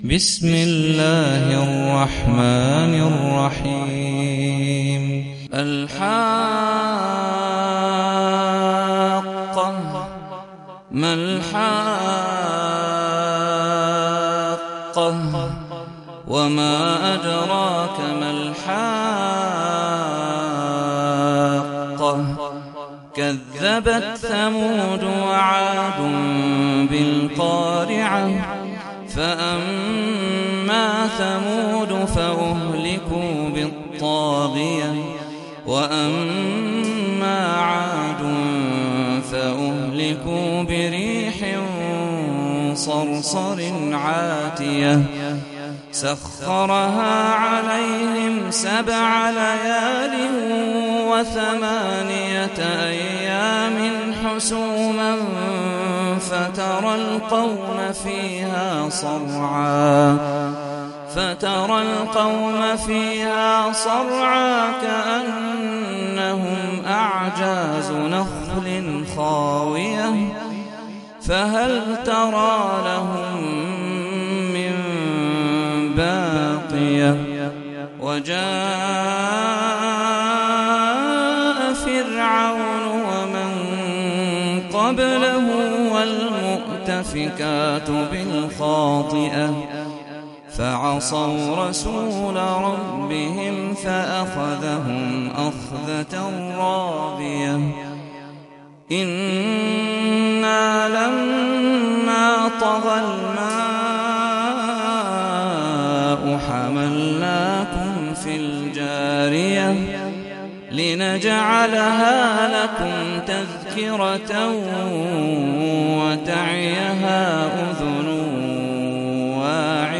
سورة الحاقة - صلاة التراويح 1446 هـ (برواية حفص عن عاصم)